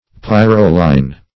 Search Result for " pyrroline" : The Collaborative International Dictionary of English v.0.48: Pyrroline \Pyr"ro*line\, n. (Chem.) A nitrogenous base, C4H7N , obtained as a colorless liquid by the reduction of pyrrole.
pyrroline.mp3